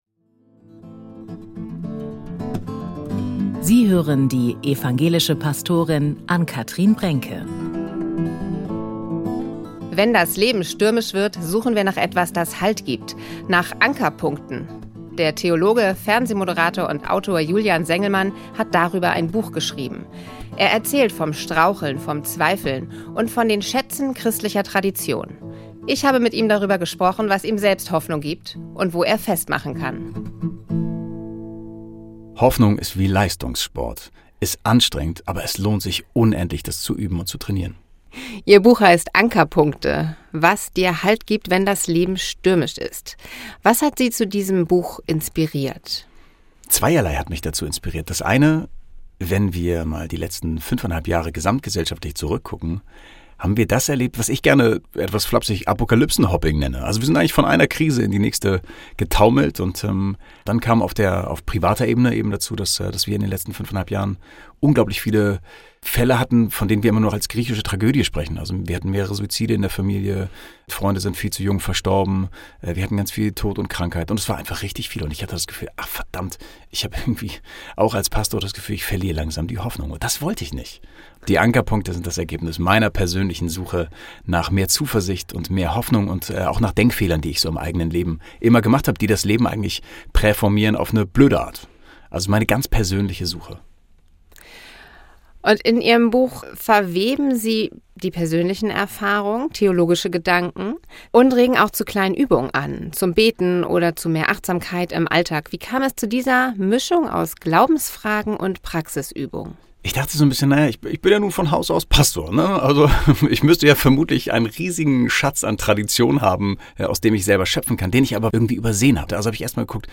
Sengelmann, Pastor, Musiker, Autor und Moderator im Gespräch mit